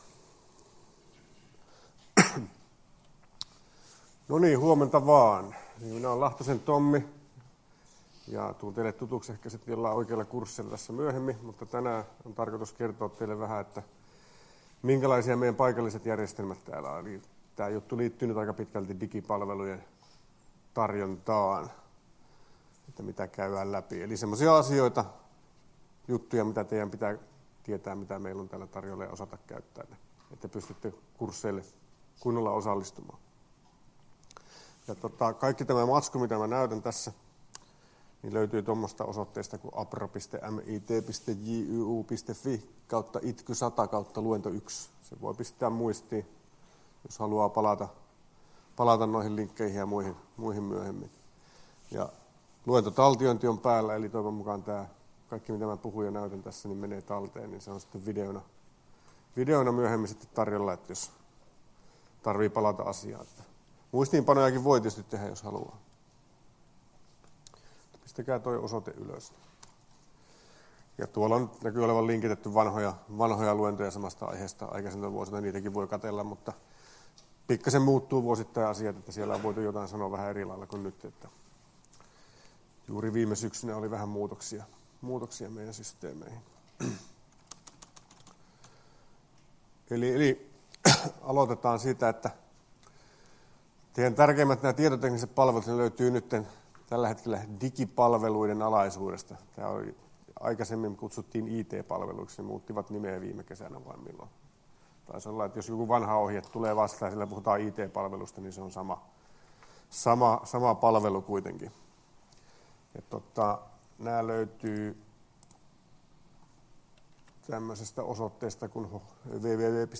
Luento 08.01.2019 — Moniviestin